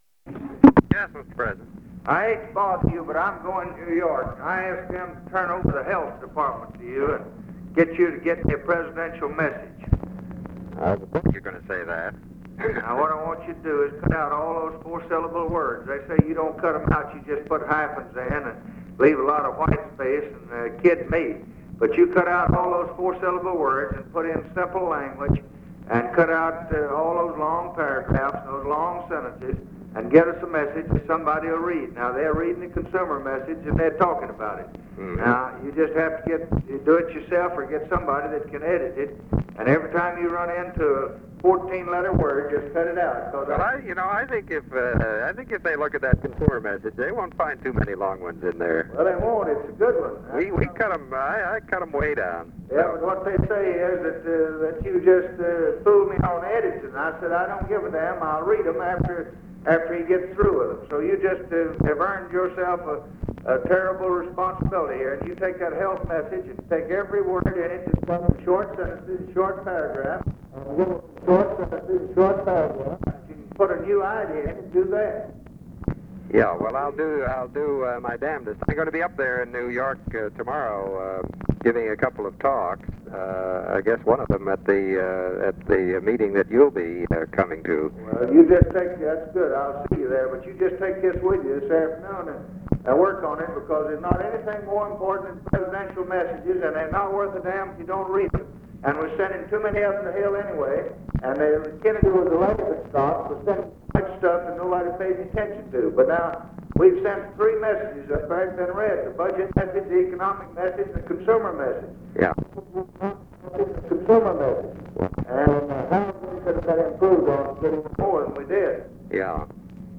Conversation with WALTER HELLER, February 5, 1964
Secret White House Tapes